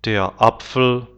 Jablko (551x640)jablko der Apfel [apfl]
der-Apfel.wav